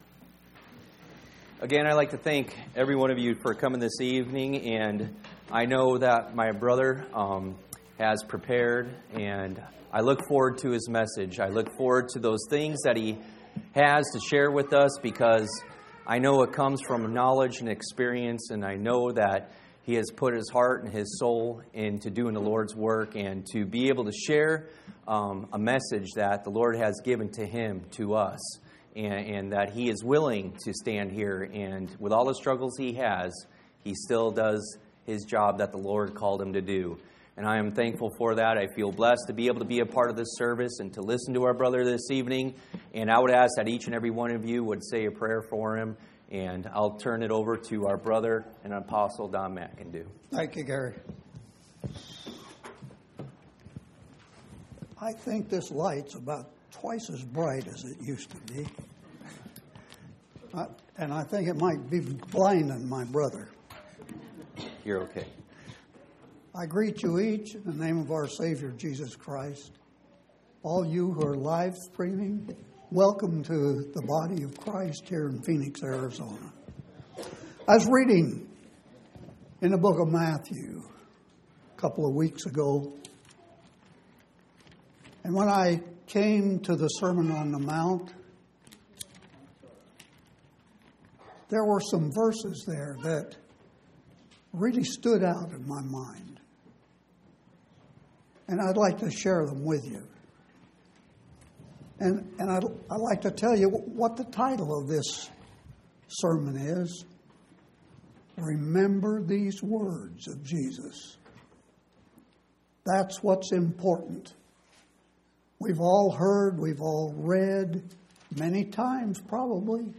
10/23/2016 Location: Phoenix Local Event